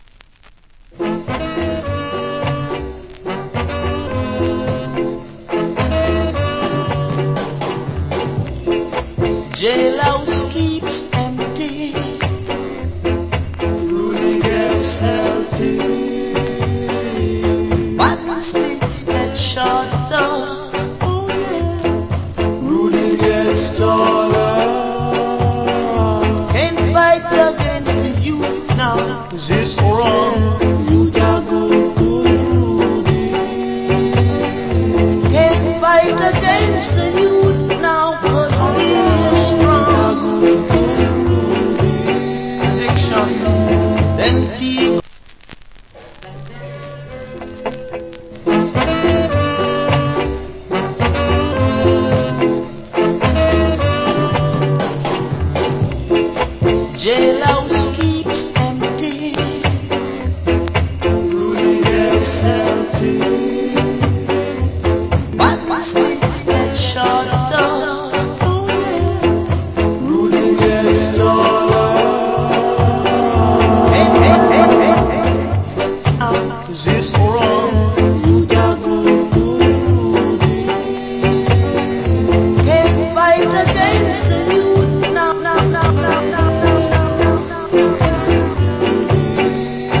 Nice Ska Vocal. Remix.